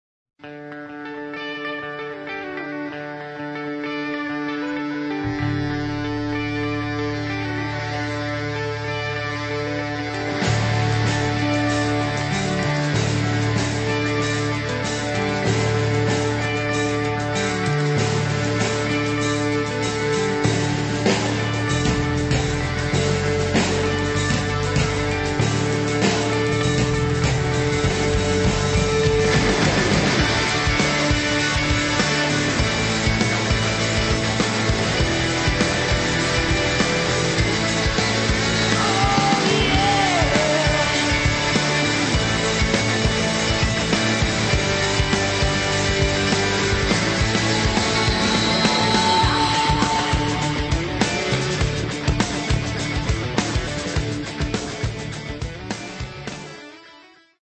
at A&M Studios